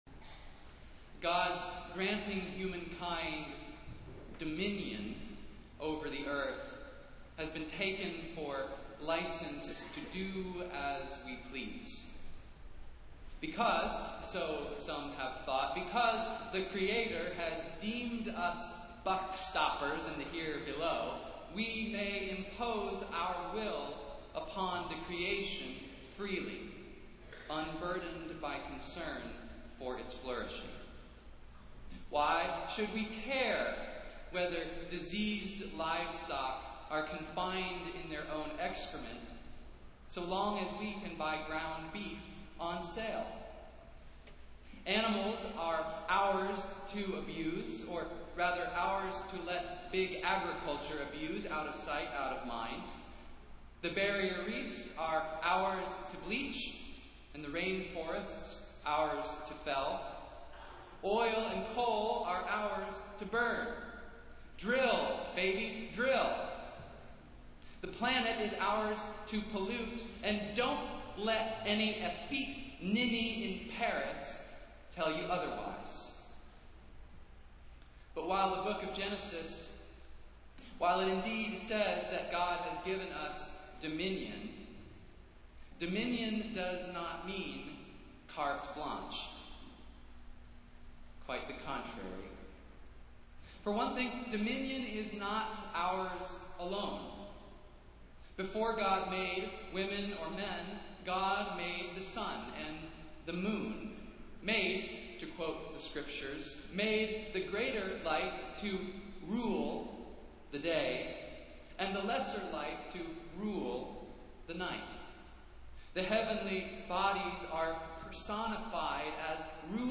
Festival Worship - First Sunday after Pentecost